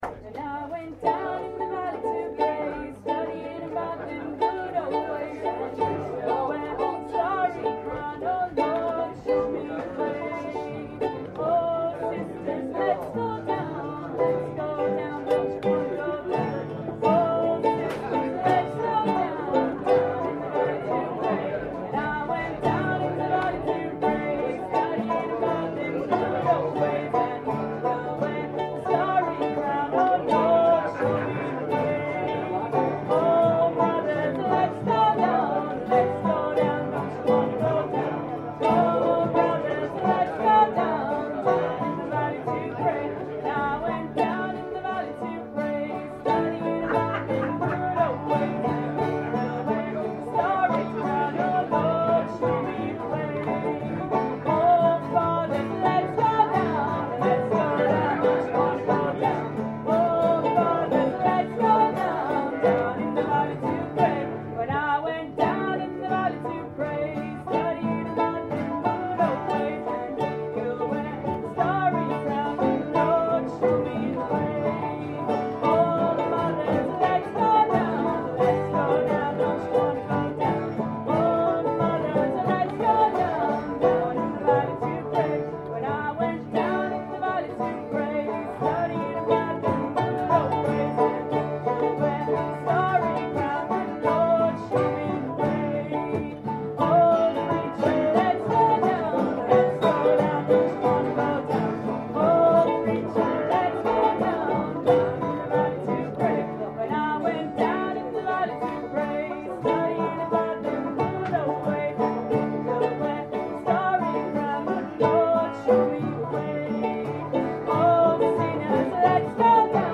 Down in the Valley. another song from Monday night's Lamb and Flag session